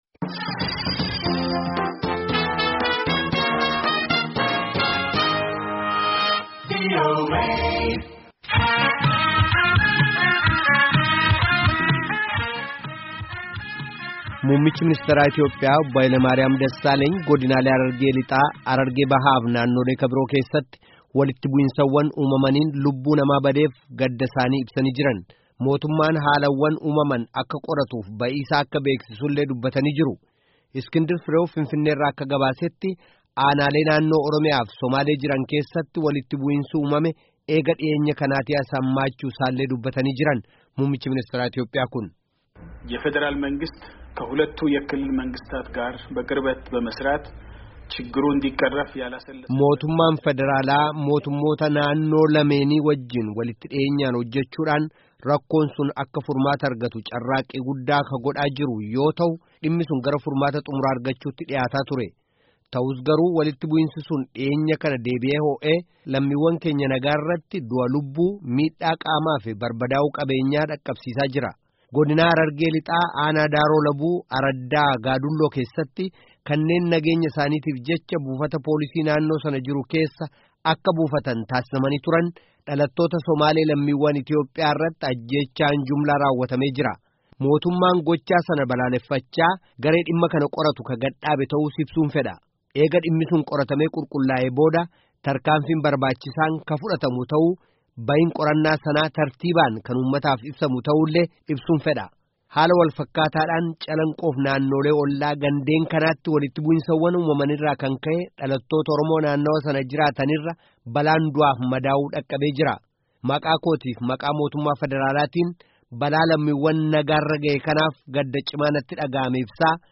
Muummichi-ministaraa Itiyoophiyaa Walitti-bu’insaa fi Mormiilee Biyyattii Keessaa Ilaalchisuun Haasawa Dhageessisan